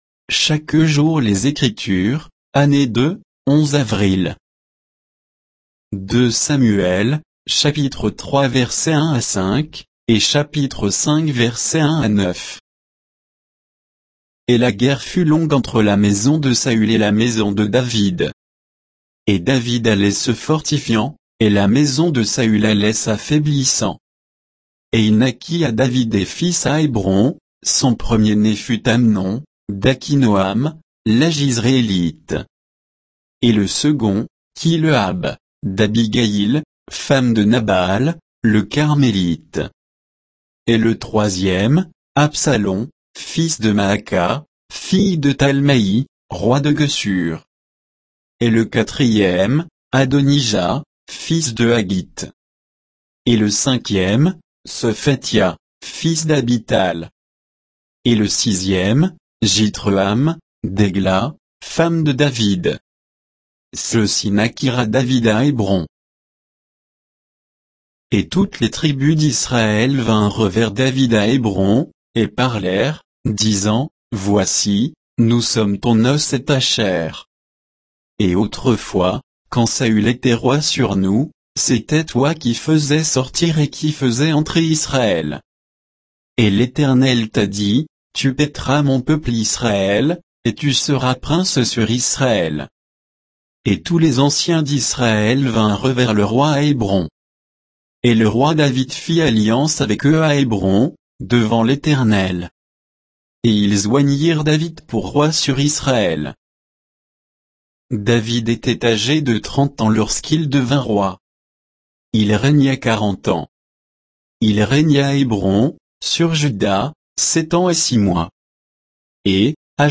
Méditation quoditienne de Chaque jour les Écritures sur 2 Samuel 3